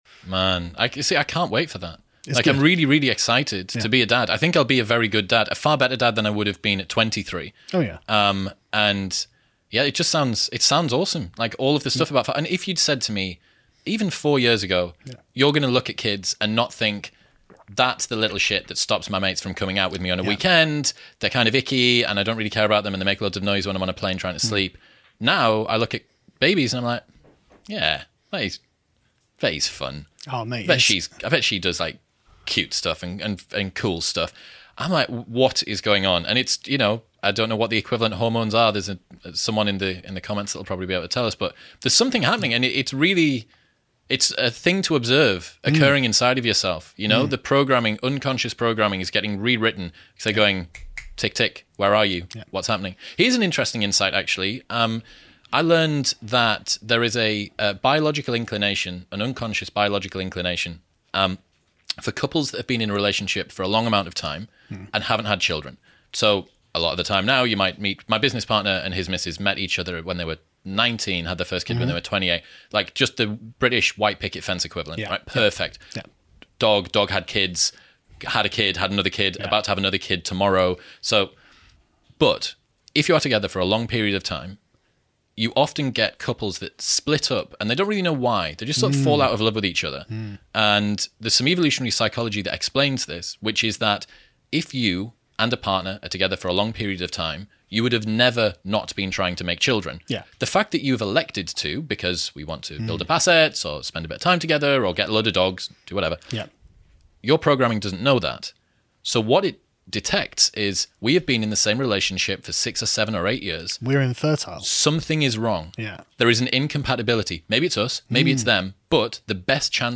PREVIEW: Interview with Chris Williamson - Fatherhood & Modern Relationships